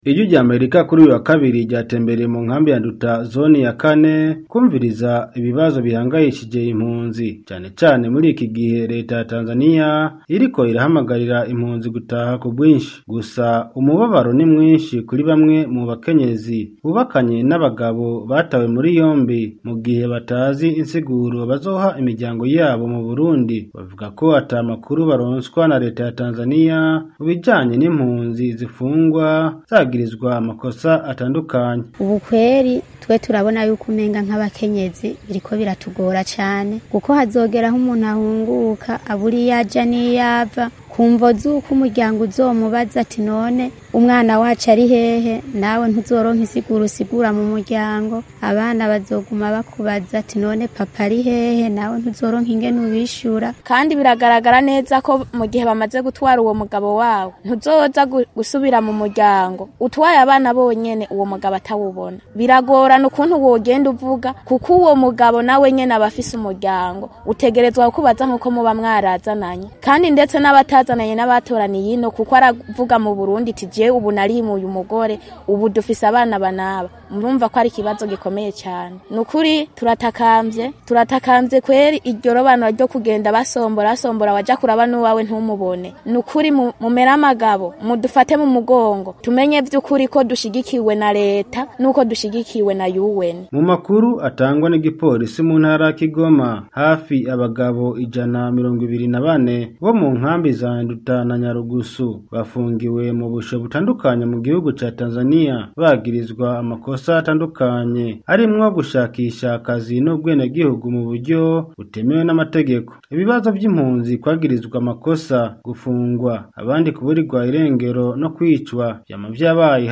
Ijwi ry’Amerika kuri uyu wa kabiri ryatembereye mu nkambi ya Nduta zone ya kane kumviriza ibibazo bihangayishije impunzi, cyane cyane muri iki gihe reta ya Tanzaniya iriko irahamagarira impunzi gutaha ku bwinshi.